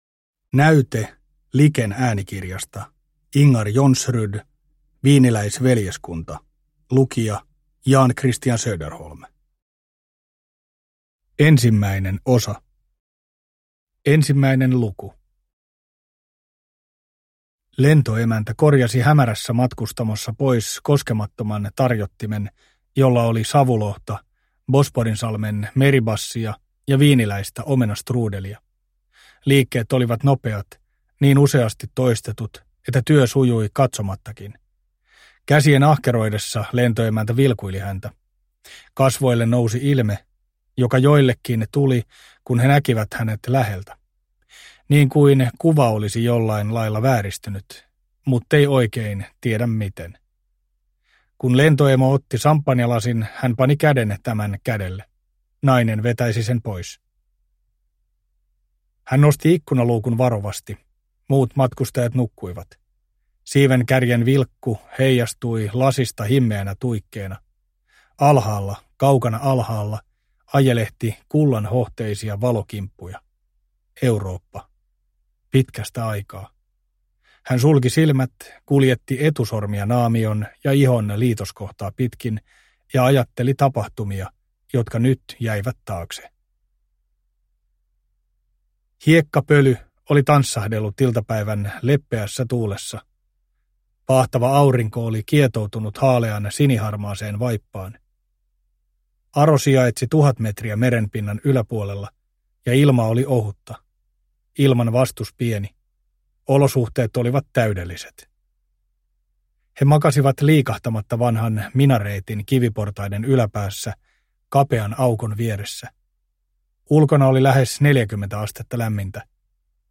Wieniläisveljeskunta – Ljudbok – Laddas ner